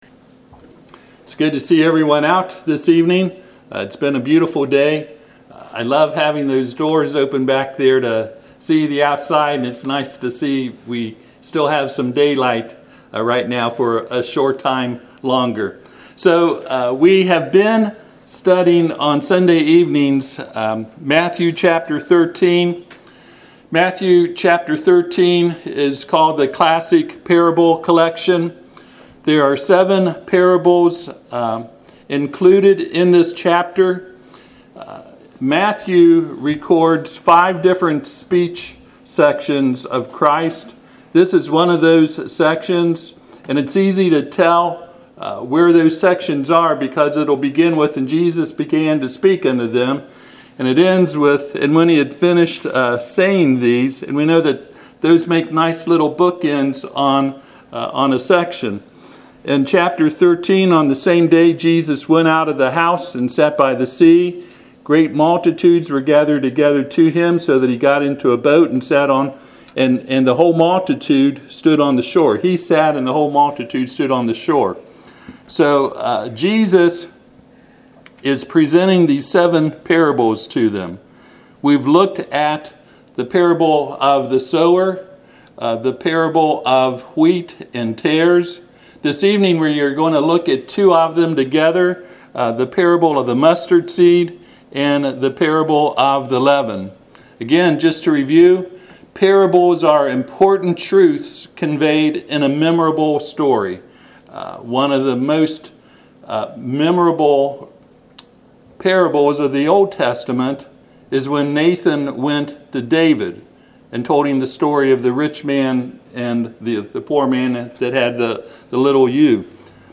Passage: Matthew 13:31-34 Service Type: Sunday PM